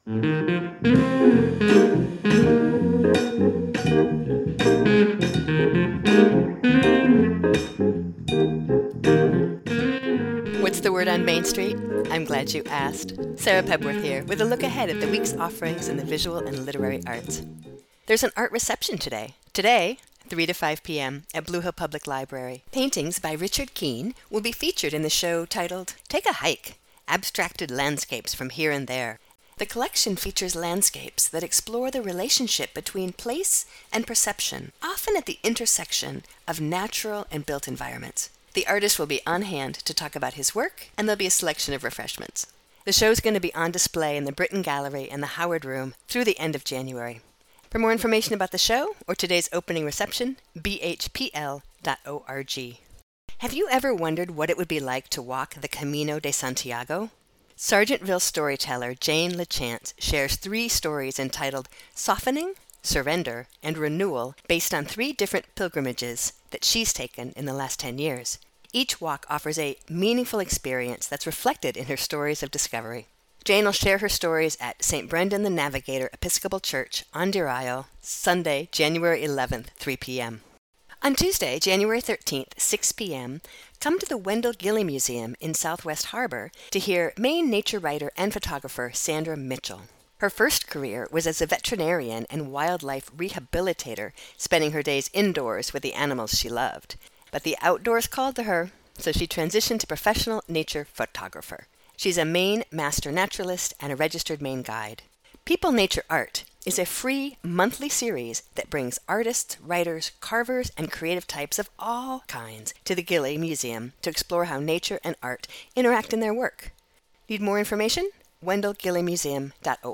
By turns both subtle and unapologetically noisy, the songs are a collection of luminous constellations, roved between by a band of texturally minded instrumental improvisers.